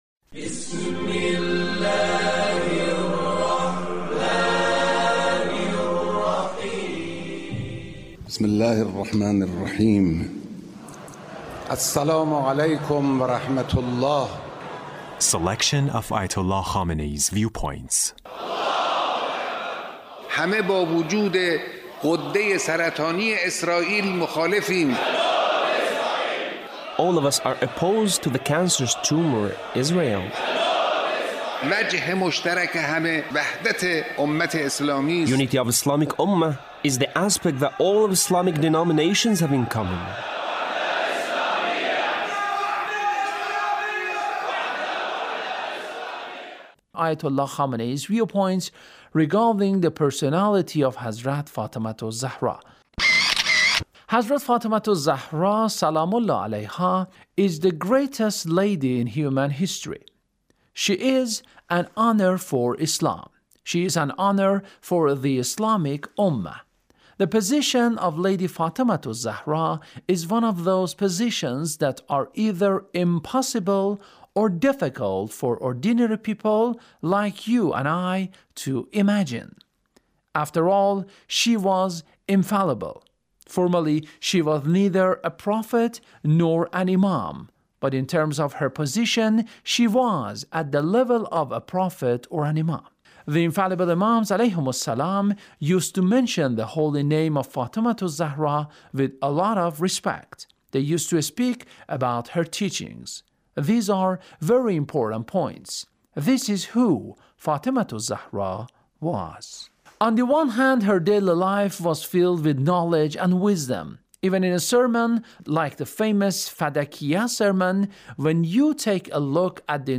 Leader's Speech (1886)